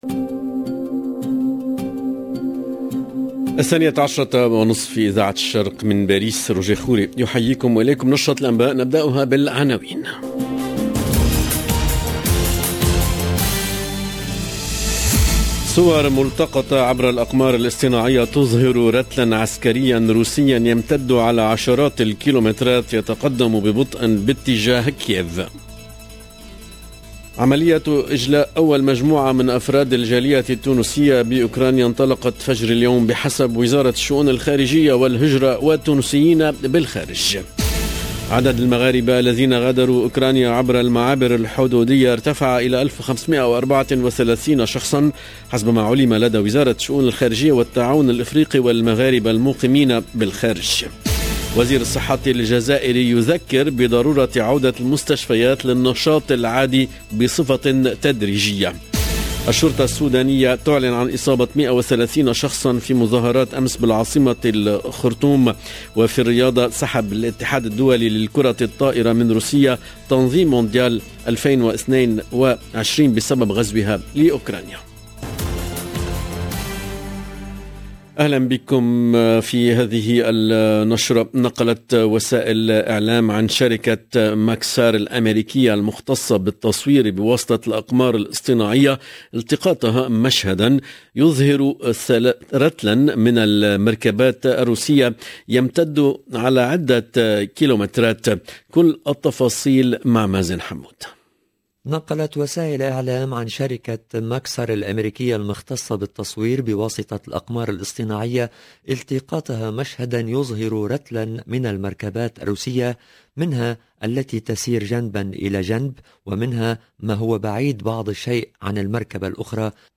LE JOURNAL DE MIDI 30 EN LANGUE ARABE DU 1/03/22